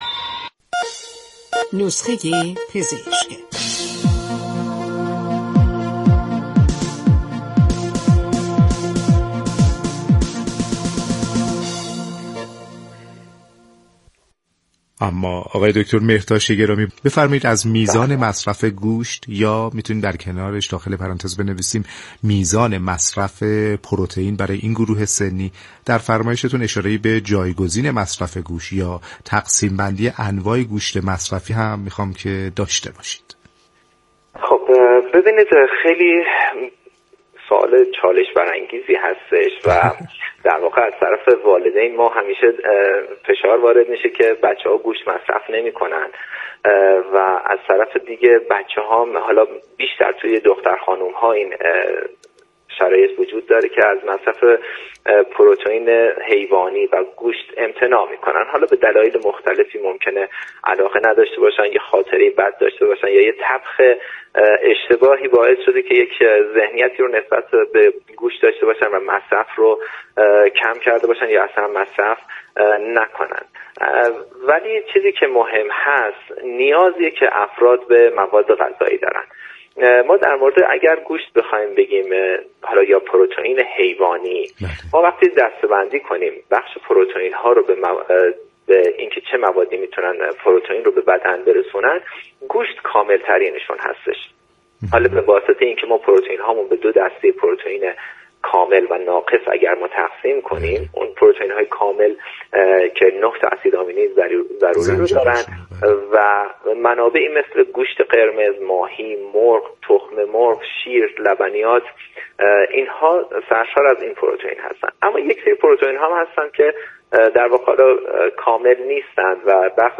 /صوت آموزشی/